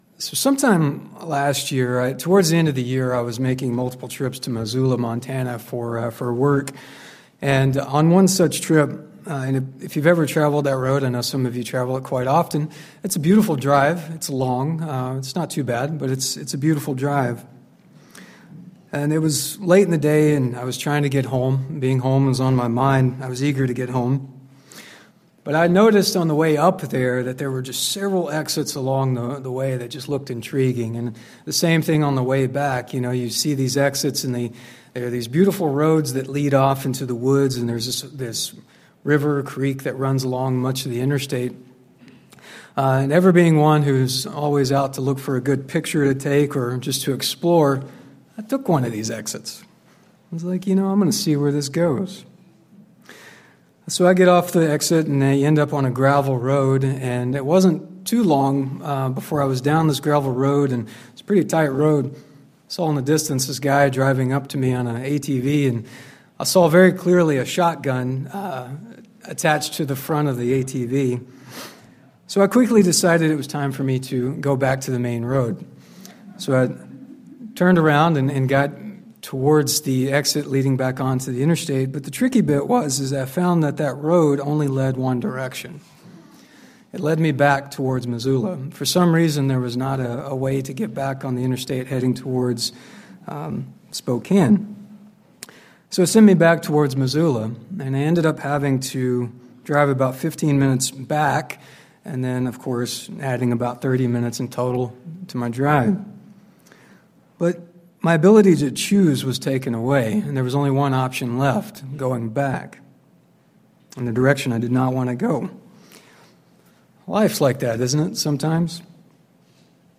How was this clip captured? Given in Spokane, WA Kennewick, WA Chewelah, WA